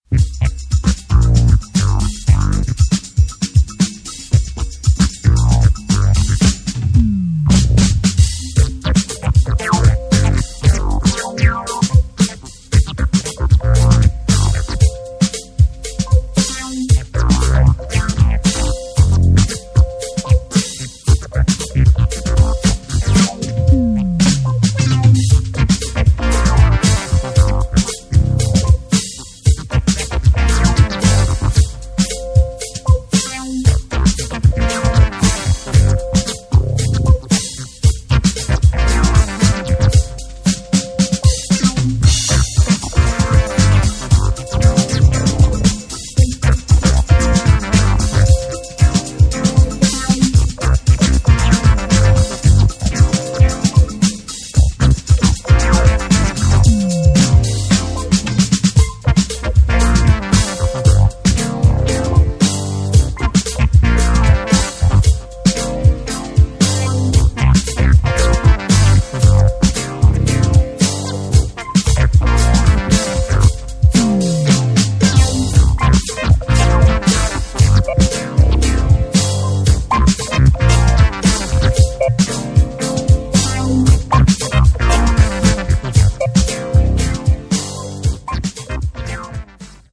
low fi synth funk and surf ambience